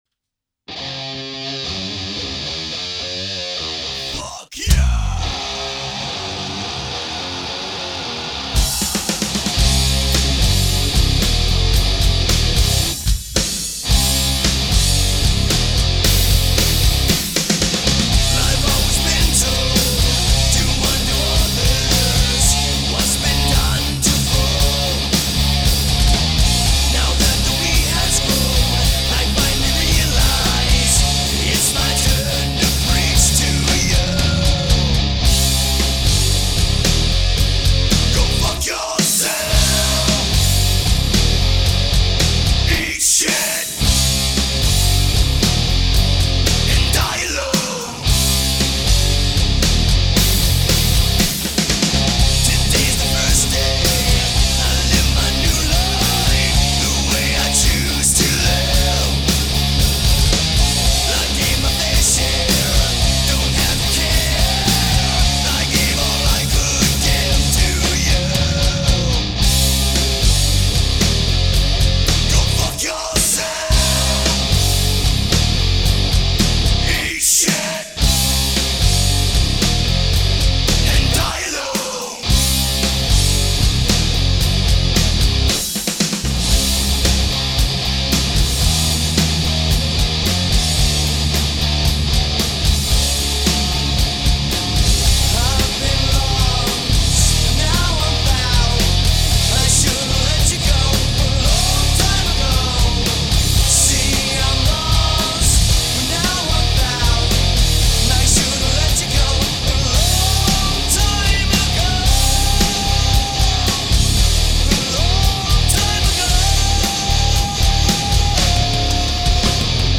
drums & vocals
guitar & vocals
bass & vocals